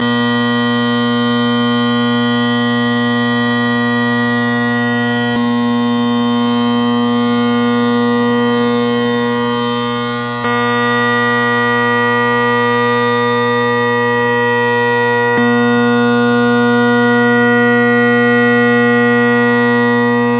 Drone Sounds of the GHB
It contains the recording of 1 set of drones with different combinations of 2 sets of drone reeds (no chanter).
Each track was independently normalized so the relative volume differences are qualitatively accurate.
1 – Canning tenors and bass
2 – Canning tenors and Selbie bass
What I note is the Cannings, the bass anyway, has a bit more buzz to it and a more ‘complicated’ sound whereas the Selbies generate very ‘pure’ tones.